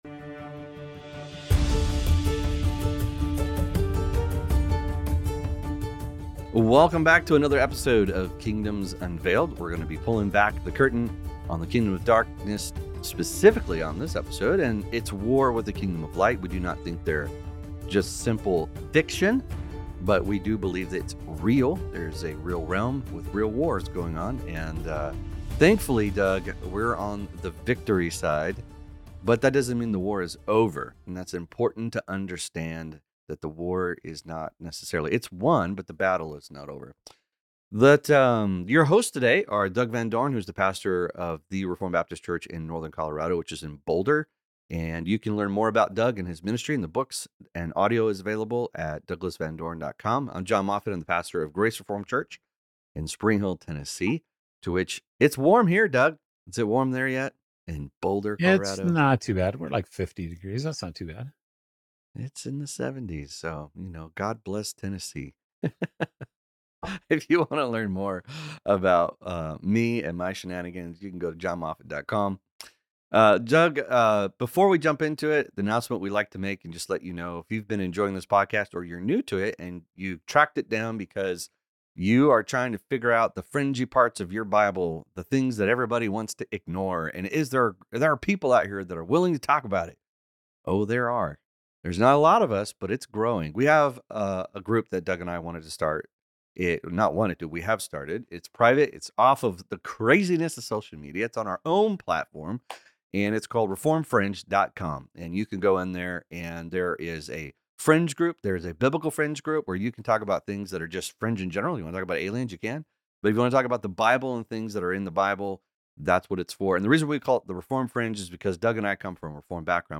We hope you find this conversation informative and encouraging!